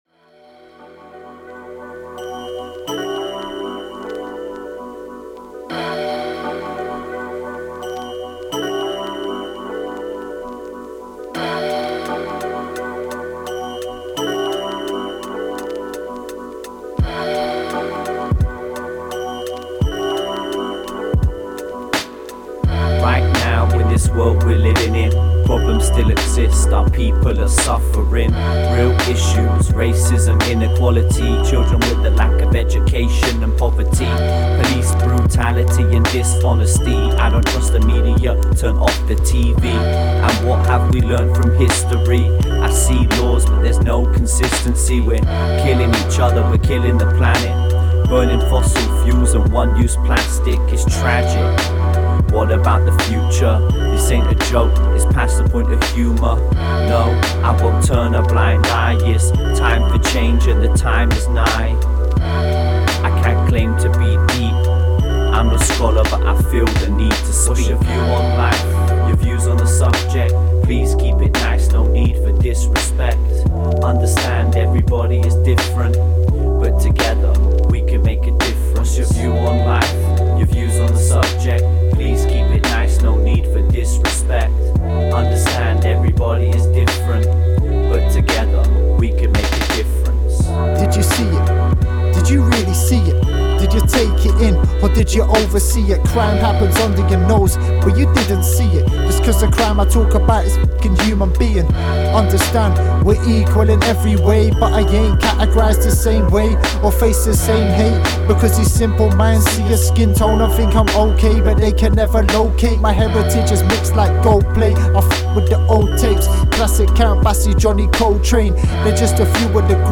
Genre: hiphop.